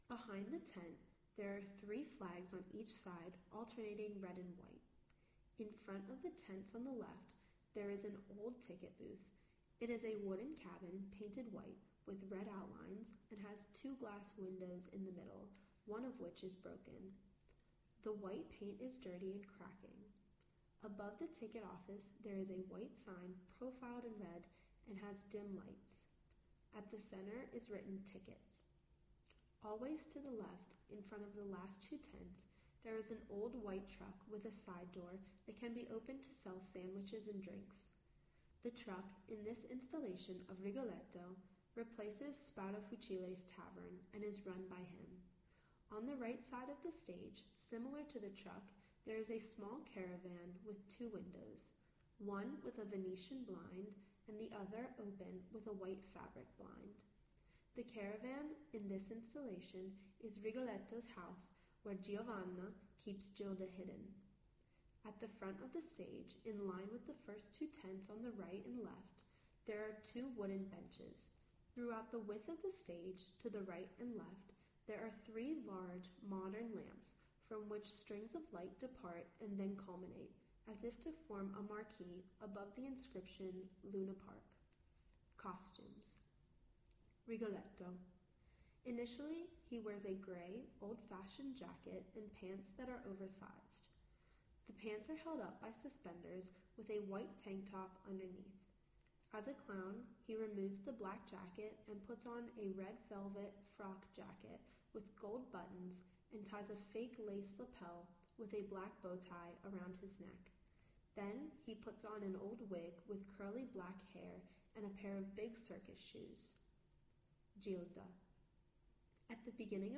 In 2019, our guests will be offered audio descriptions in Italian for all the operas on stage, prerecorded introductory descriptions in English, tactile tours for blind people, and Italian Sign Language tours for deaf people, to go behind the scenes and discover the set, props, wardrobe, make-up, and musical instruments.
Italian and English pre-recorded introductory descriptions include details provided by the artists themselves in order to highlight the style of and the ‘philosophy’ behind the productions.